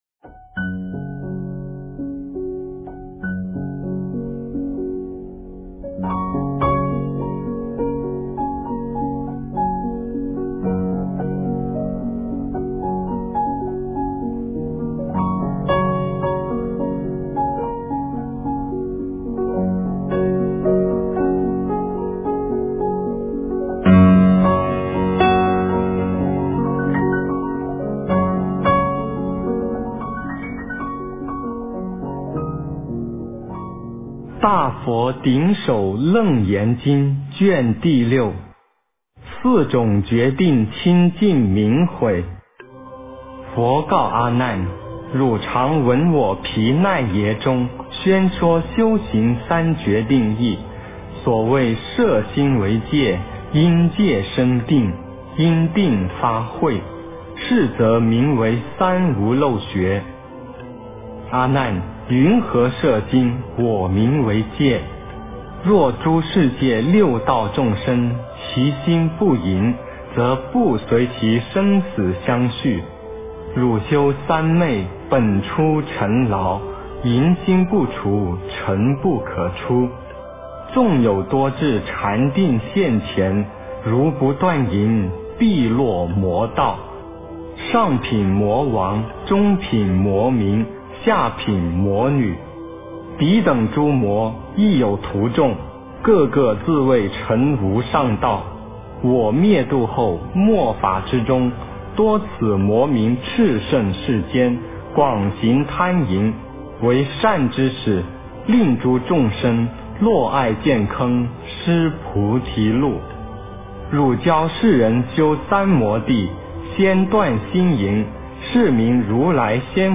四种清净明诲 - 诵经 - 云佛论坛